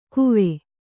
♪ kuvēṇi